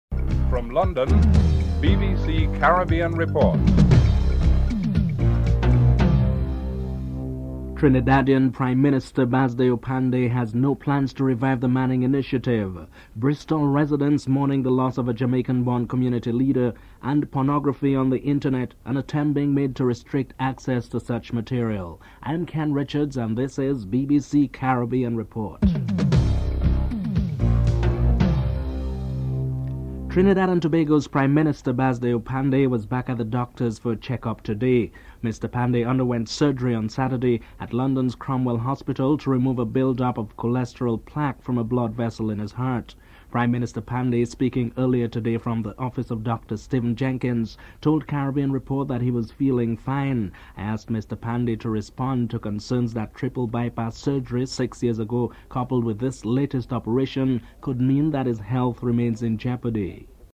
Bristol residents are interviewed (04:58-08:20)